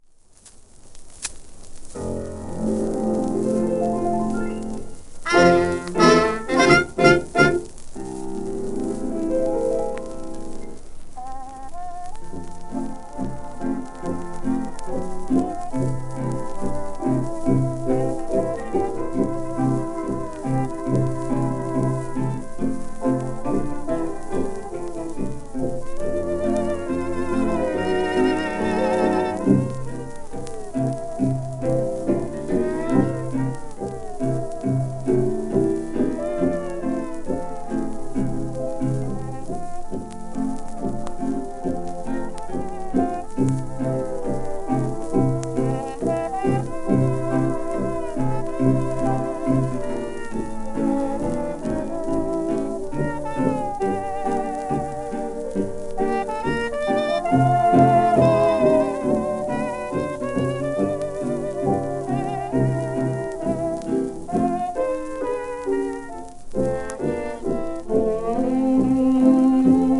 20世紀前半のアメリカで人気を博したエンターテイナーでバンドリーダー。